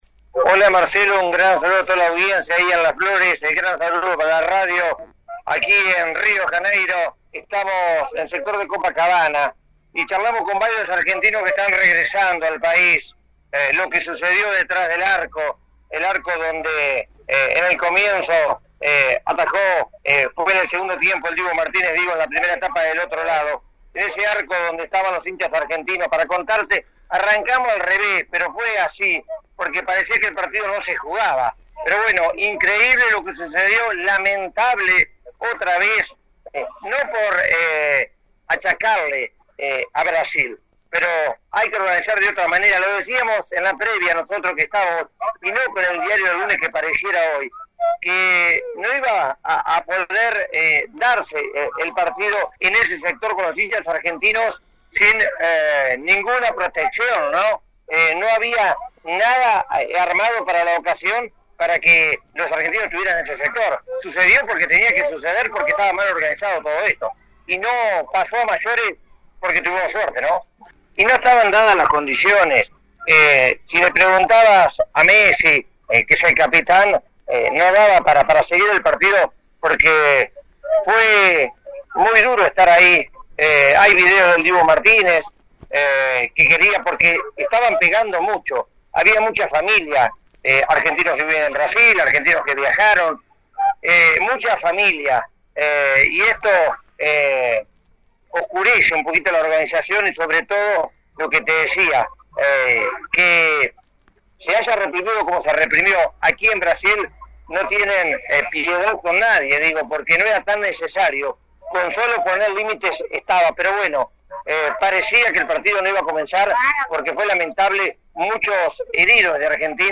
contó vía telefónica a FM Alpha detalles de lo sucedido en la conferencia de prensa posterior donde el técnico Lionel Scaloni puso en duda su continuidad.